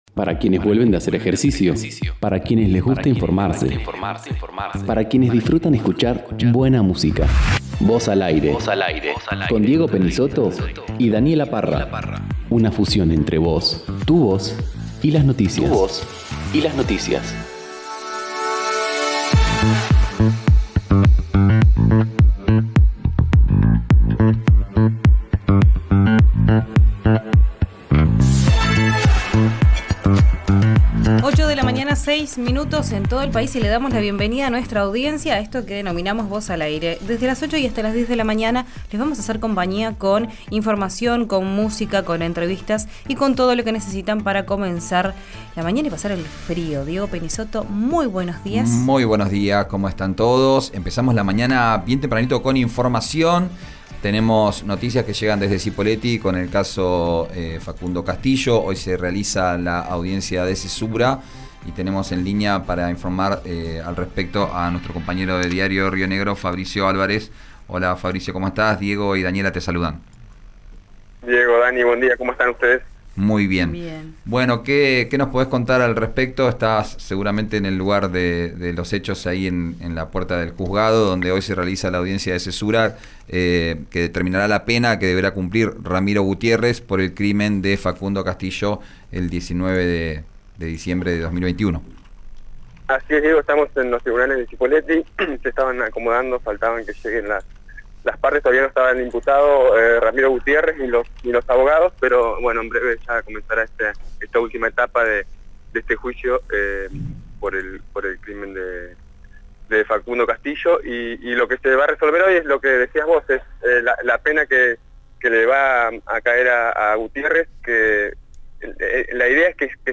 RÍO NEGRO RADIO